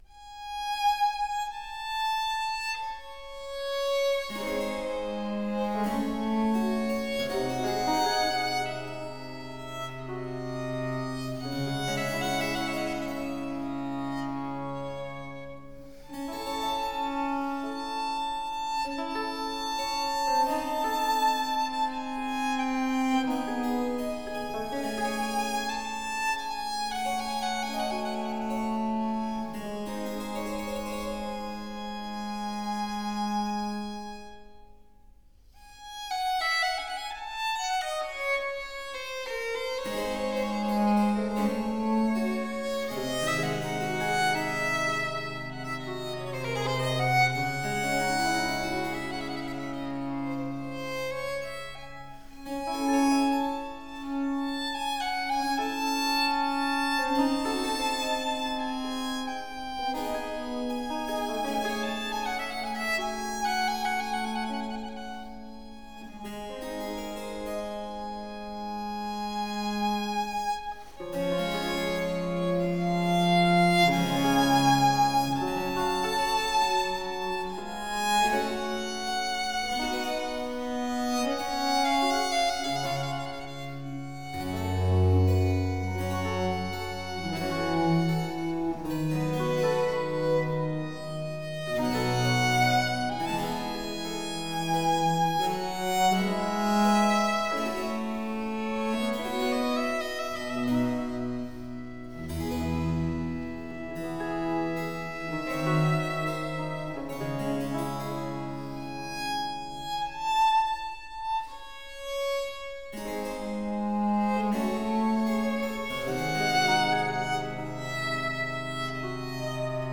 Violino barocco
ESEMPI DI ORNAMENTAZIONE
Sonate a violino o flauto e basso (Dresda, 1716), Sonata n.5, 3° mov.